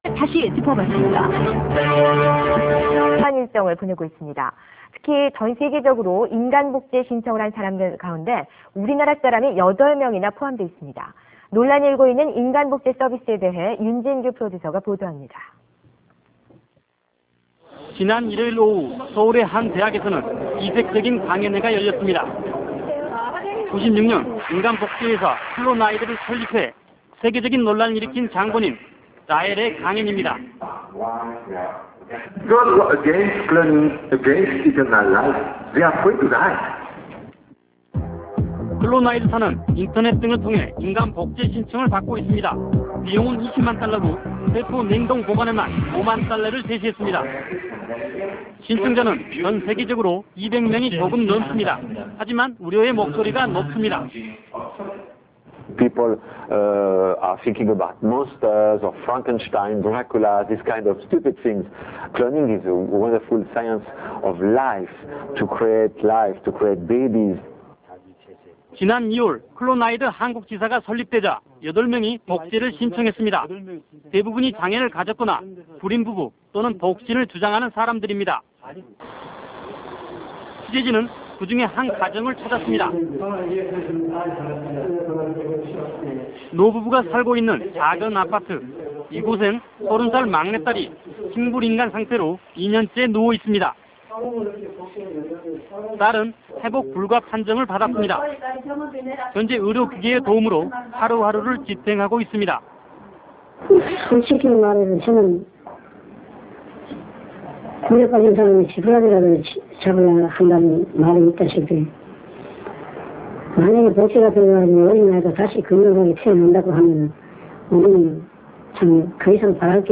생명복제회사 클로나이드(Clonide) 사장 겸 외계인 메시야설 주장자인 이 미친 인간은 99.8월 연세대 방문 강연에서 인간이 과학기술을 통해 "영원한 생명"을 얻을 수 있다는 사단의 소리를 학생들에게 불어넣고 있다.